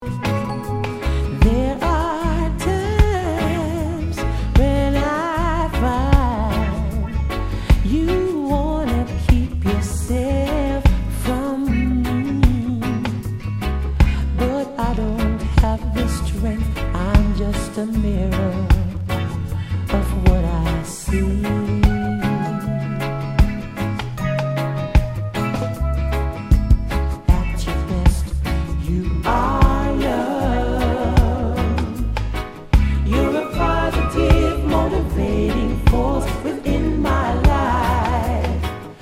Category       レコード / vinyl 7inch
Tag       LOVERS/ROOTS REGGAE